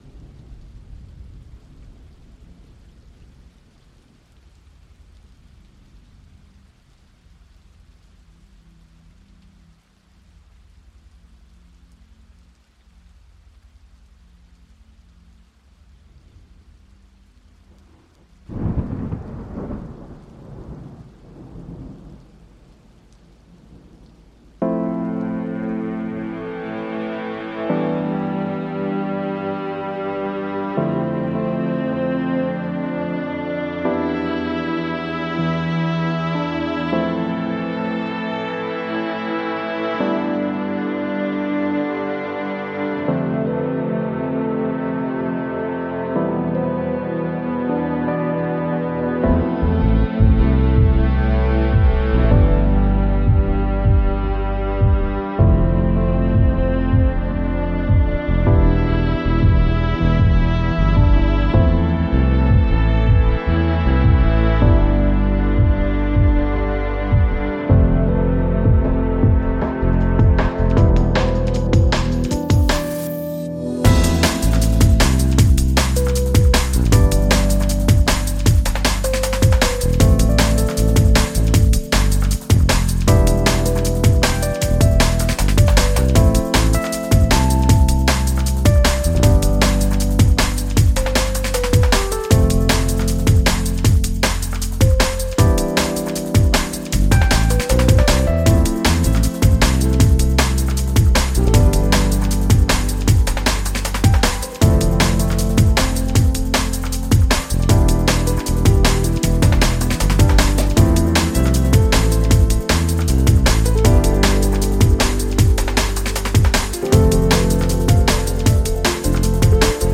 (The beginning drags a bit, but stay with it)
purely in Reason when we were about 17-18 back in 2003 during one night.